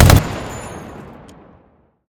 gun-turret-shot-3.ogg